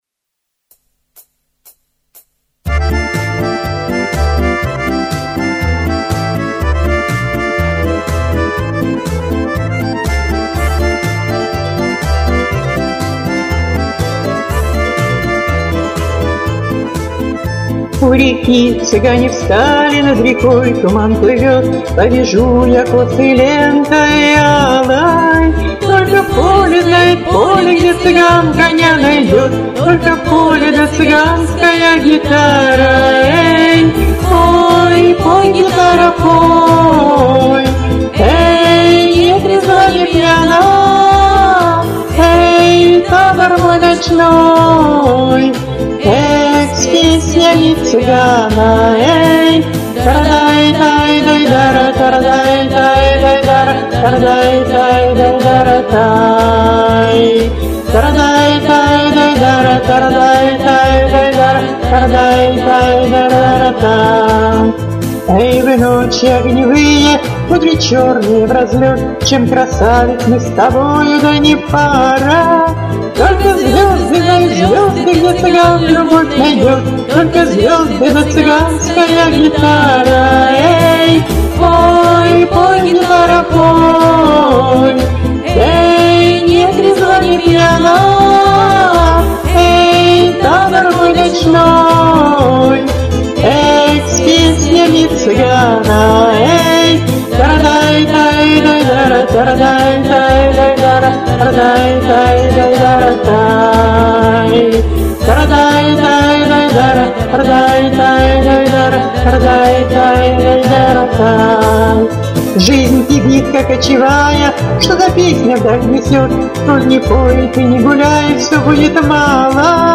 И гитара спела!!!